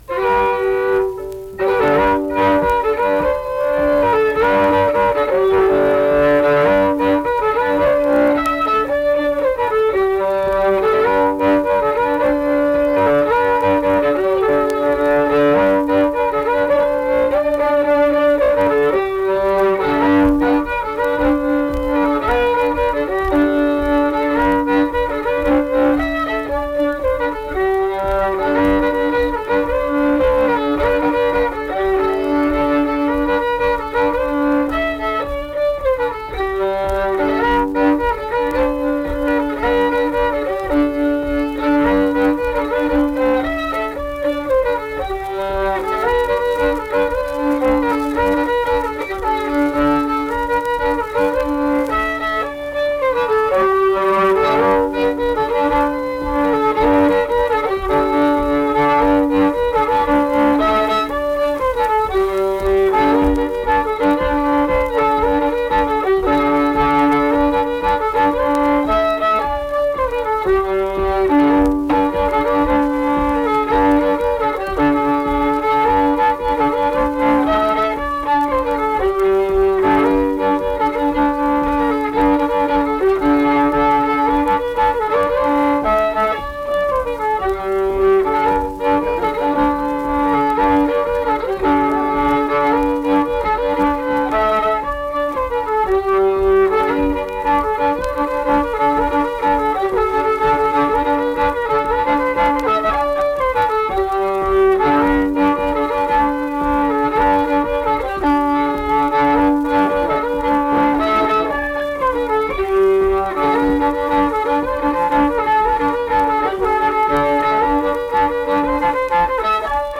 Accompanied guitar and unaccompanied fiddle music performance
Instrumental Music
Fiddle
Pocahontas County (W. Va.), Mill Point (W. Va.)